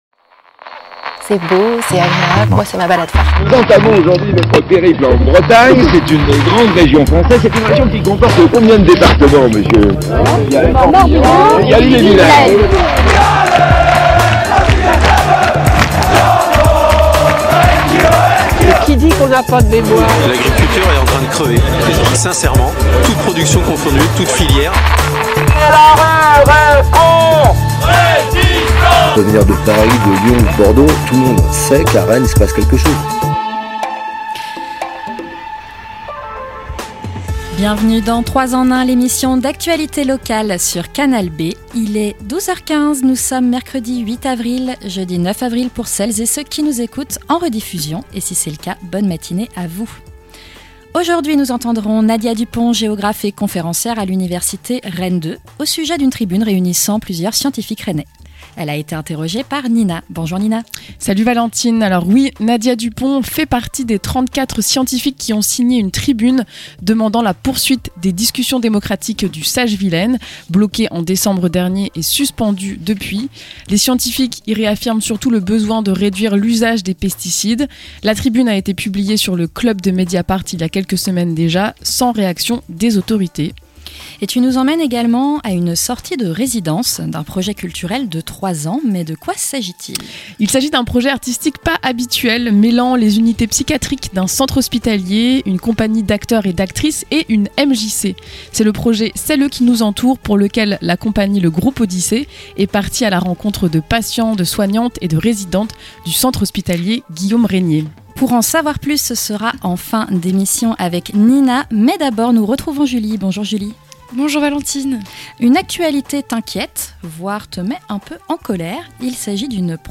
L'interview
Le Reportage